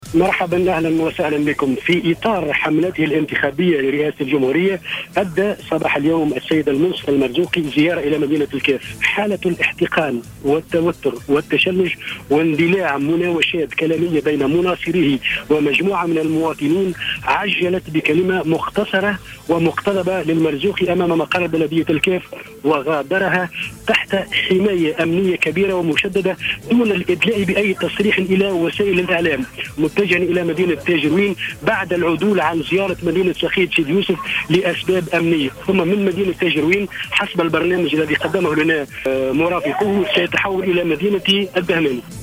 أدى صباح اليوم السبت المرشح للانتخابات الرئاسية في دورتها الثانية المنصف المرزوقي زيارة لولاية الكاف وألقى خلالها كلمة مقتضبة، أكد فيها أنه لا سبيل للعودة إلى العهد البائد،وفق ما أكده مراسل "جوهرة اف أم" بالجهة.